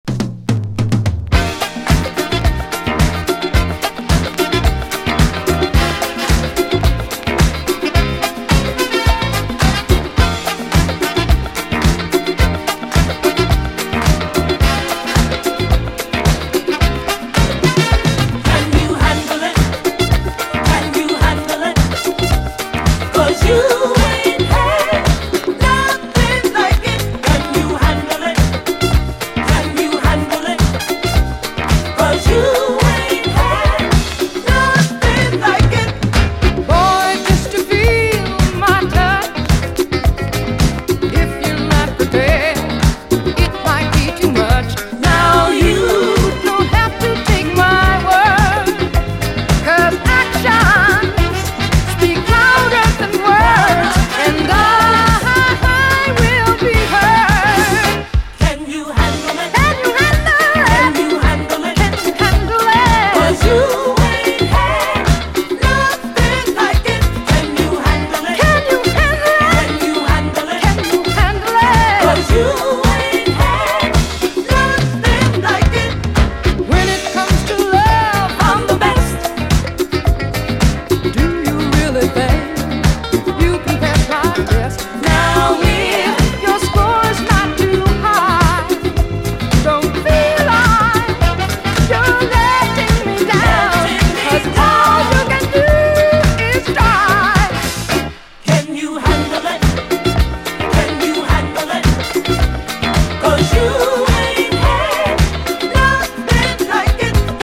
SOUL, 70's～ SOUL, DISCO, 7INCH